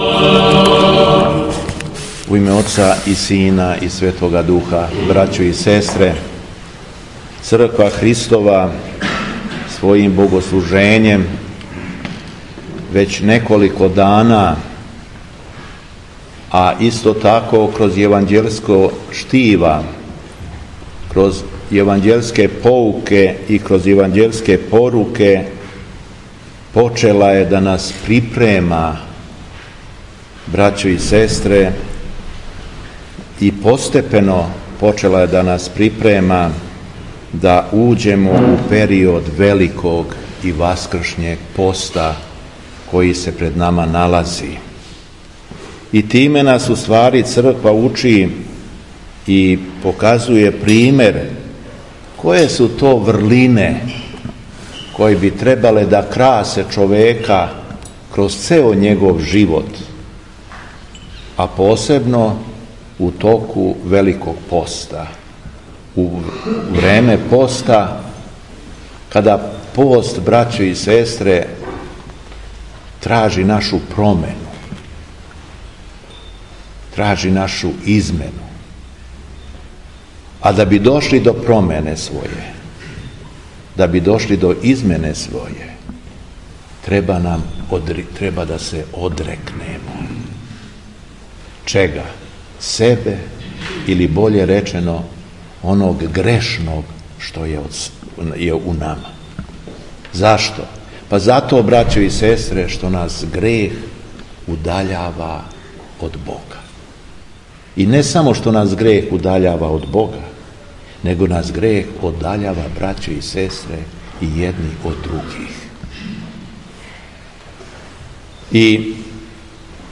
У Недељу о Блудном сину, 20. фебруара 2022. године, када наша Света Црква прославља и празнује Светог Партенија Лампсакијског и Светог Теодора Комоговског, Његово Преосвештенство Епископ шумадијски Господин Јован служио је Свету Архијерејску Литургију у храму Благовести у Рајковцу.
Беседа Његовог Преосвештенства Епископа шумадијског г. Јована